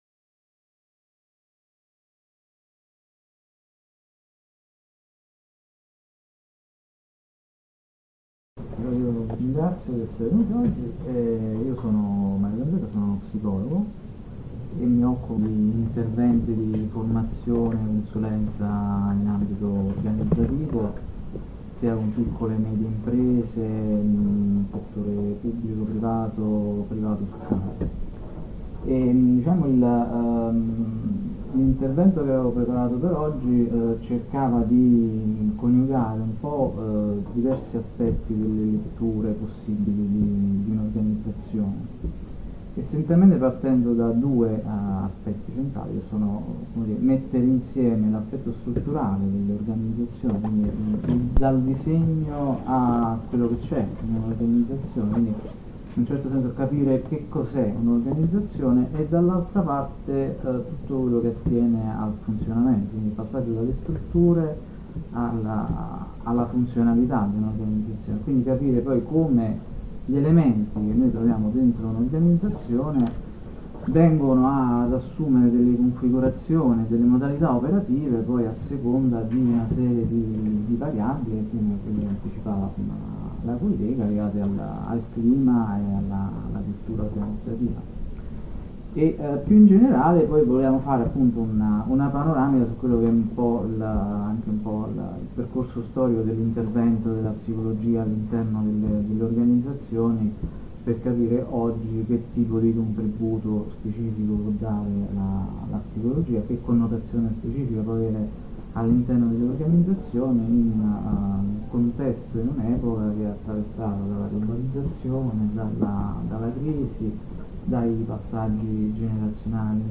A queste ed altre domande cerco di rispondere in un seminario sulle dimensioni strutturali e funzionali delle organizzazioni e sull’evoluzione delle forme di intervento psicologico nelle organizzazioni volte a coniugare lo sviluppo della capacità produttiva e del benessere delle persone che vi operano.